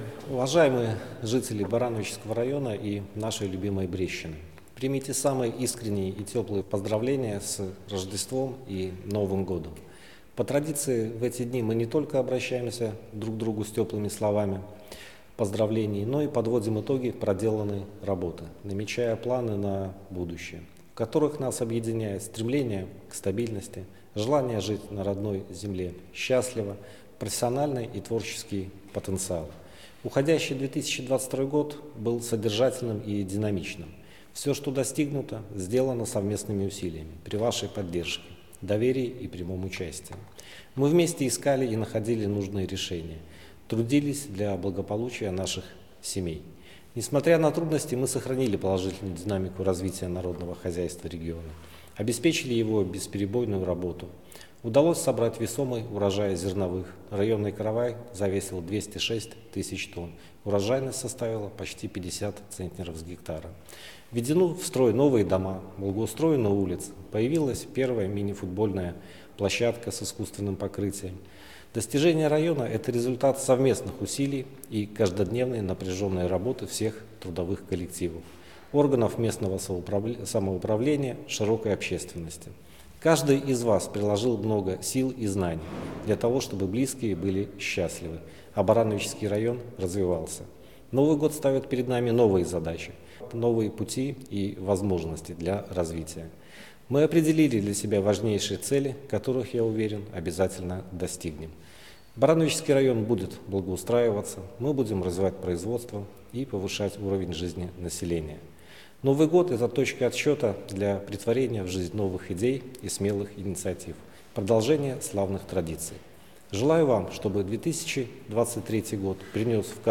Поздравление с Новым годом председателя Барановичского районного исполнительного комитета Сергея Карпенко